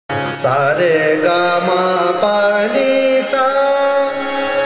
ThaatKhammaj
ArohaS R G m P N S’